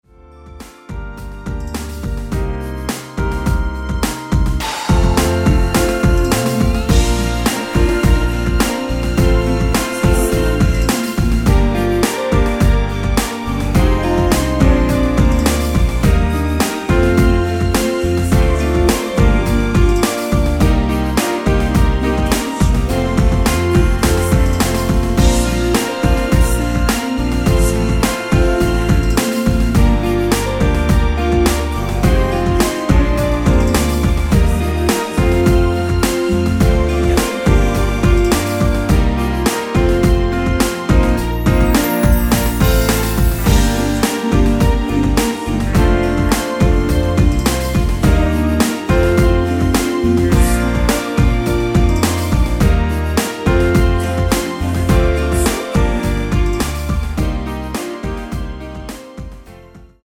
(-1) 내린 코러스 포함된 MR 입니다.(미리듣기 참조)
Bb
◈ 곡명 옆 (-1)은 반음 내림, (+1)은 반음 올림 입니다.
앞부분30초, 뒷부분30초씩 편집해서 올려 드리고 있습니다.